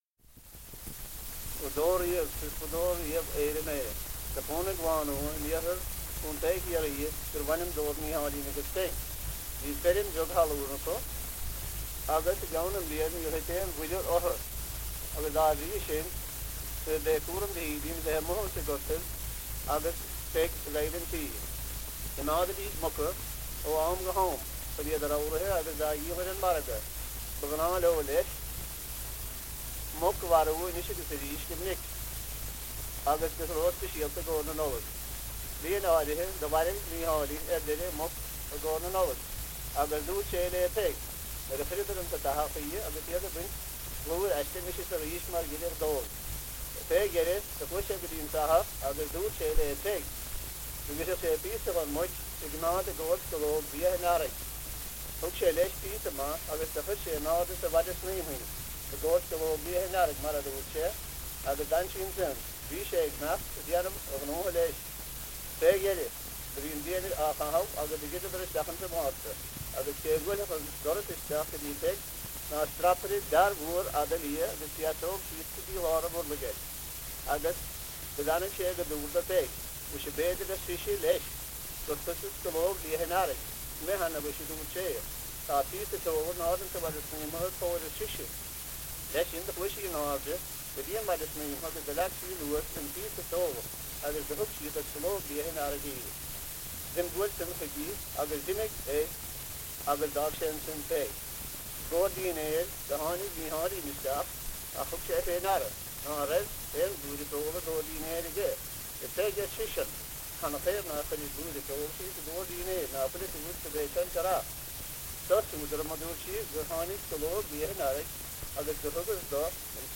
• Tales -- Ireland
• Accents and dialects - Irish language - Ireland: Kerry